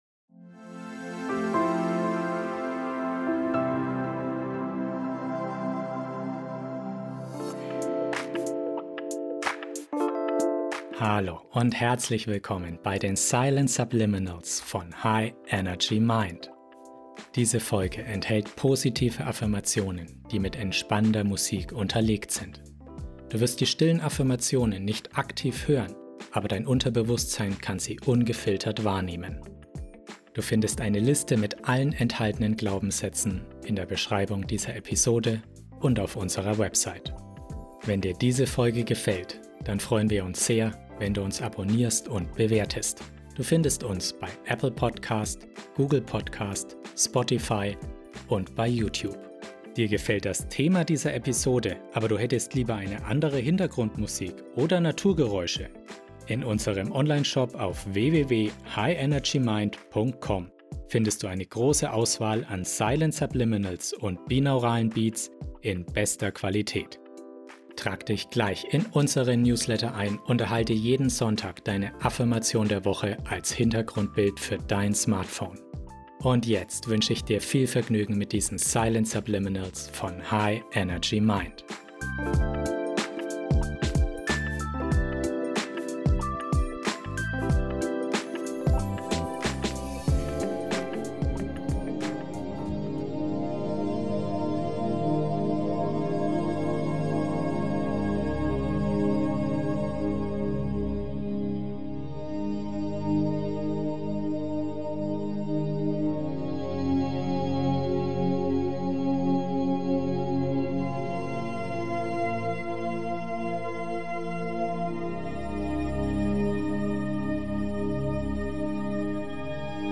Die beruhigende Musik in 432 Hz begleitet kraftvolle Silent Subliminals, die dein Unterbewusstsein erreichen und dich von negativen Einschränkungen befreien...